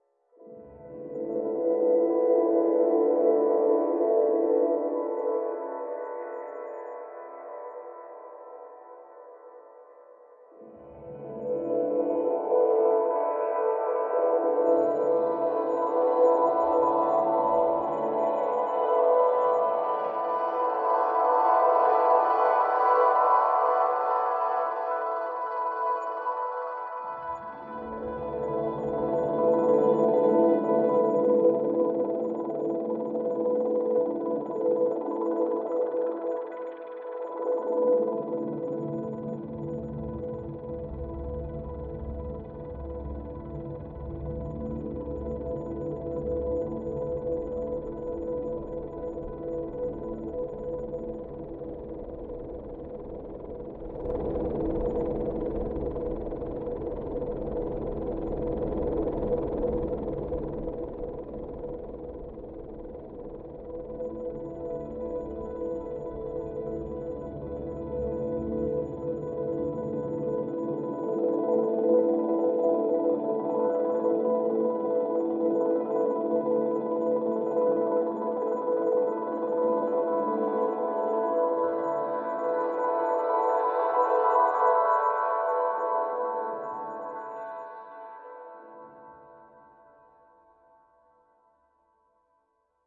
科幻外星人和未来主义的声音和气氛 " 科幻TNT 1 B3 2
描述：一个科幻的空间声音。
标签： 气氛 气氛 电子 音乐 加工 科幻 合成器
声道立体声